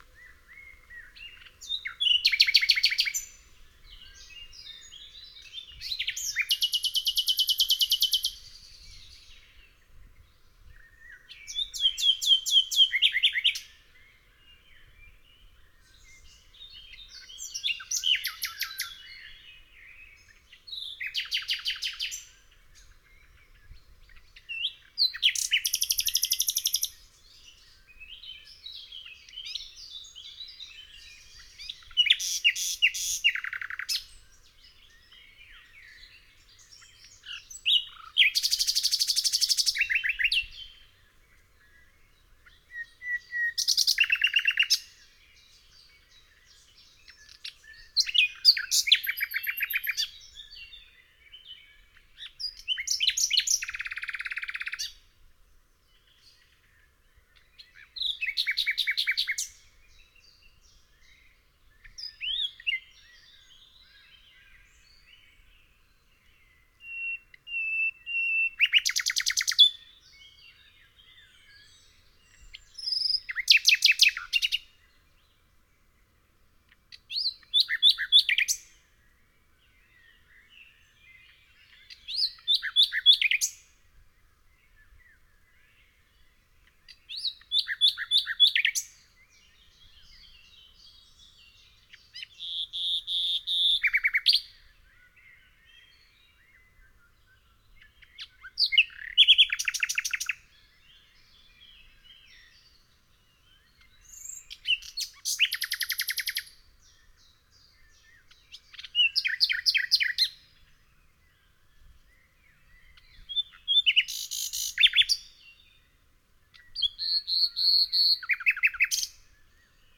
day_birds.ogg